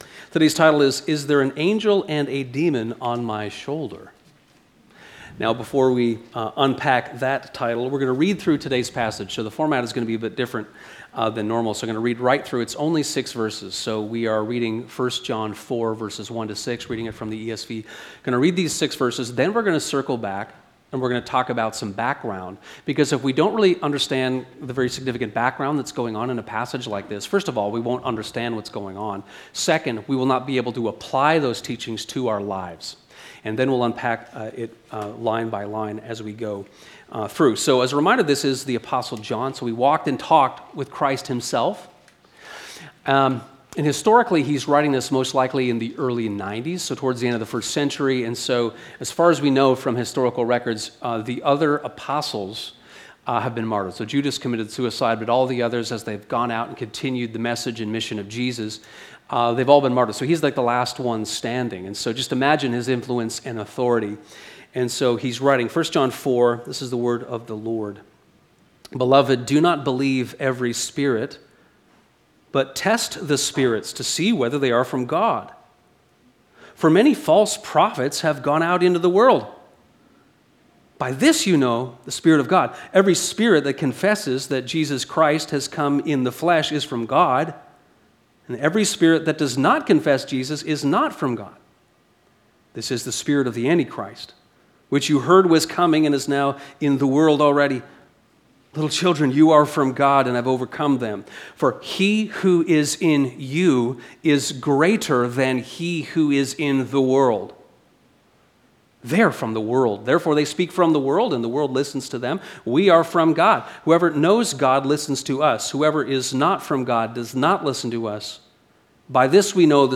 Sermons | Westminster
This sermon explains 1 John 4:1-6. We can’t hide from spiritual warfare.